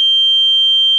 SnootGame/game/audio/effects/tinnitus.ogg at e1b0979e6c6f4ba0cf0fc12d1b22bccf4d5741ad
tinnitus.ogg